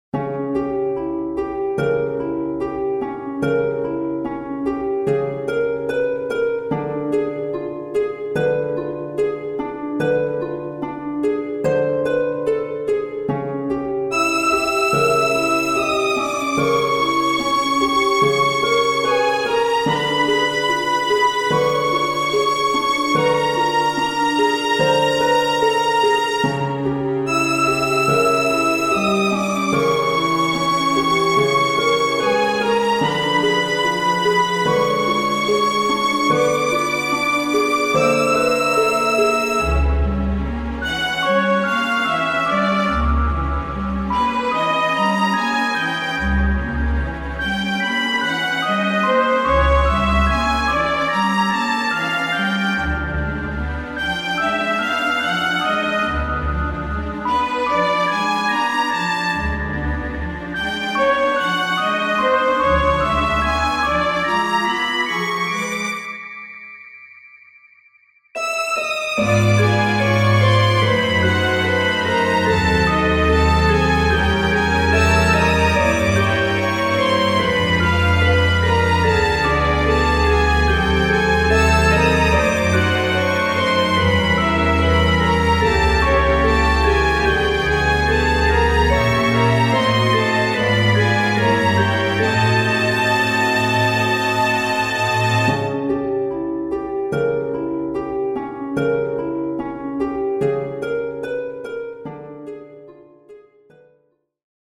フリーBGM イベントシーン ホラー・不気味・不穏